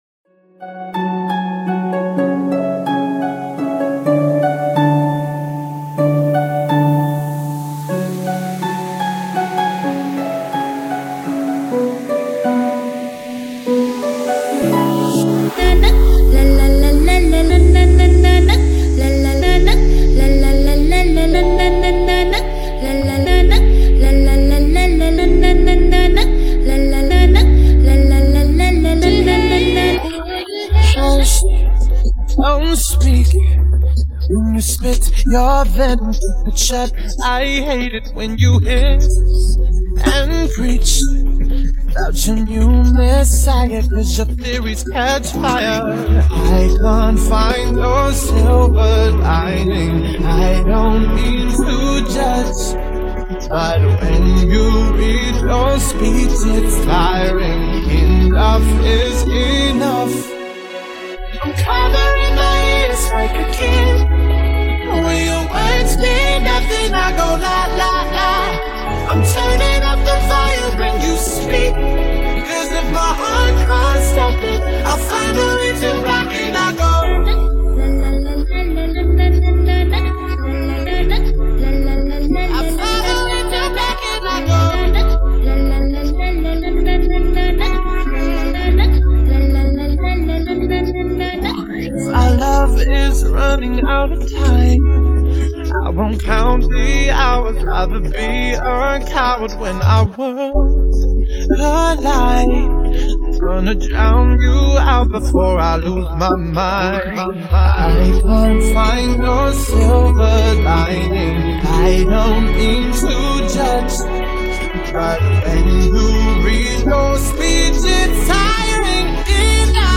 欧美歌曲